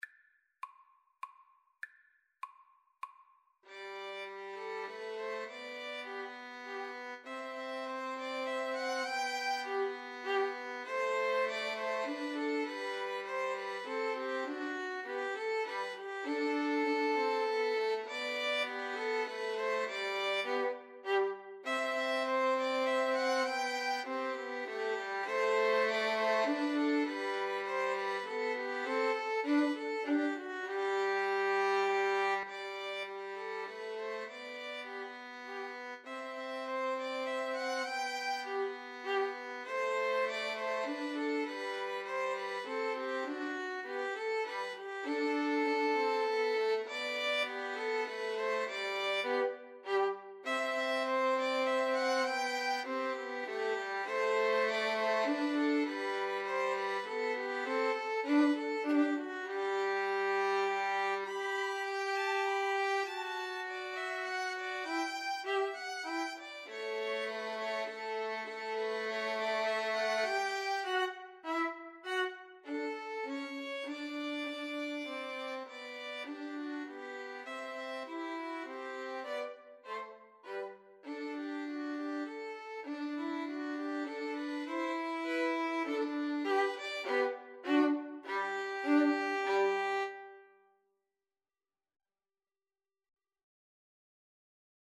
Free Sheet music for Violin Trio
G major (Sounding Pitch) (View more G major Music for Violin Trio )
3/4 (View more 3/4 Music)
Classical (View more Classical Violin Trio Music)